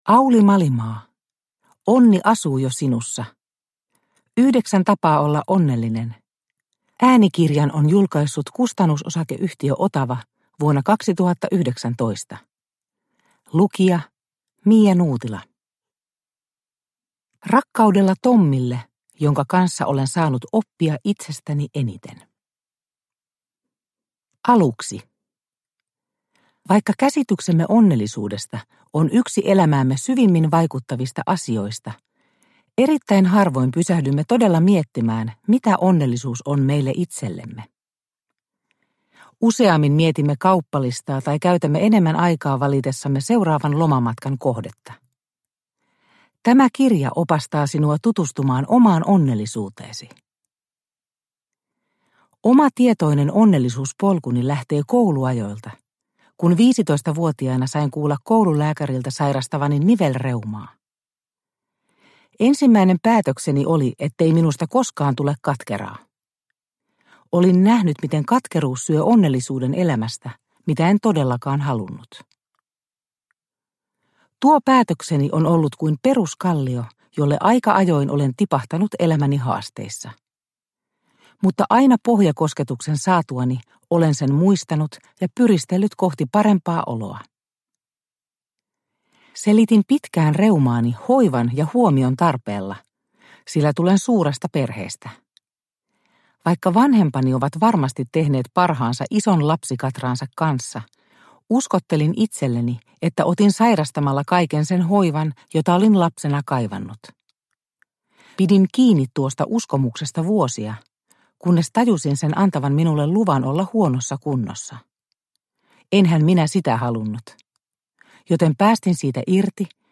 Onni asuu jo sinussa – Ljudbok – Laddas ner